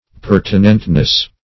Per"ti*nent*ness, n.